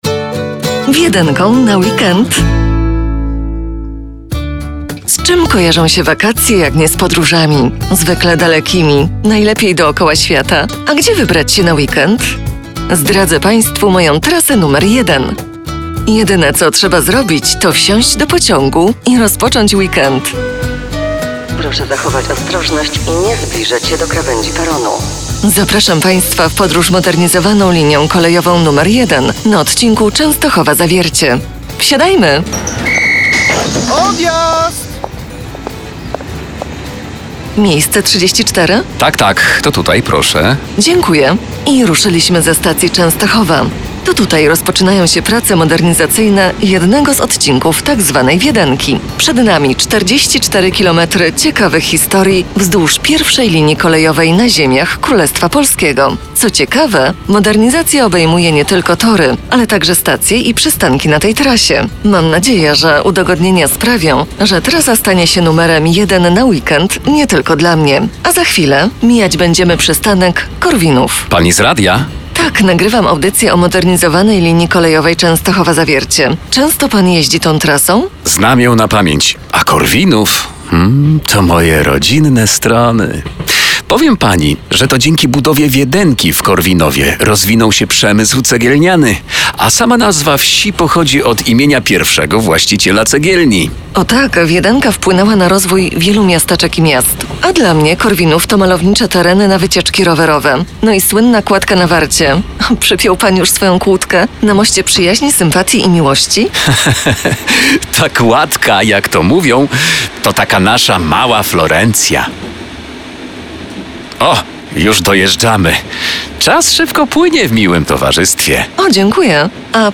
audycja radiowa